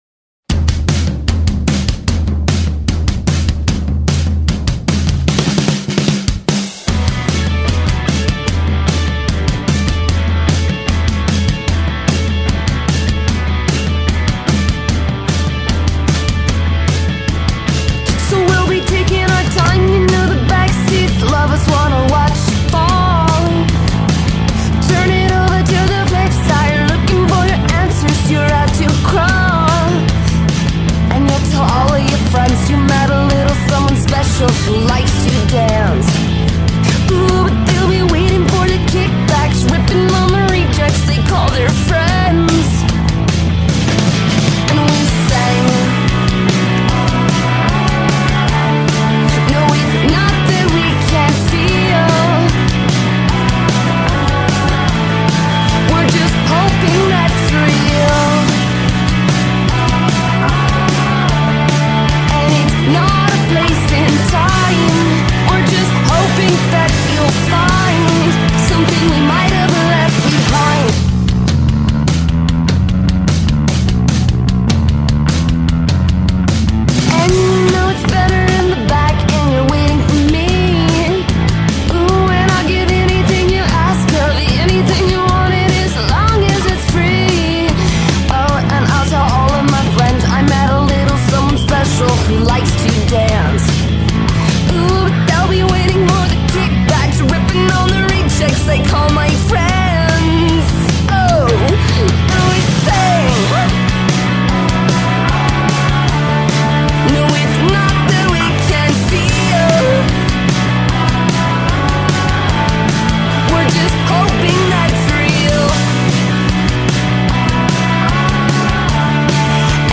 Minneapolis indie rock group